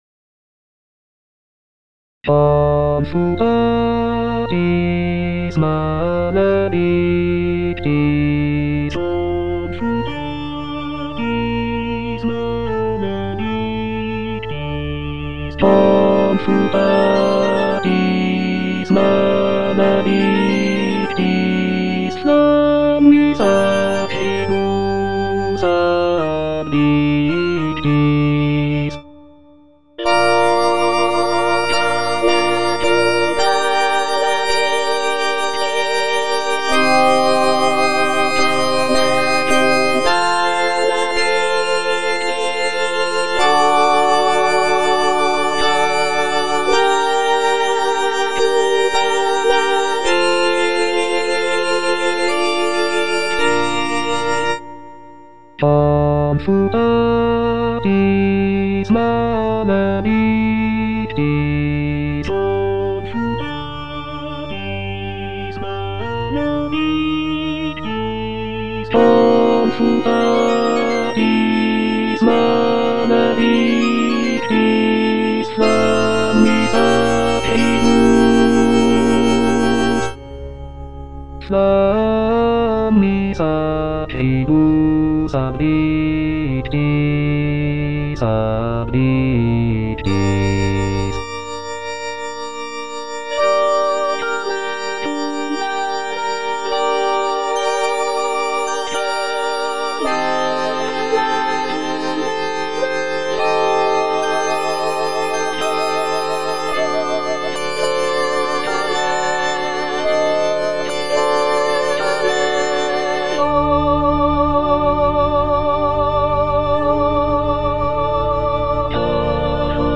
(bass II) (Emphasised voice and other voices) Ads stop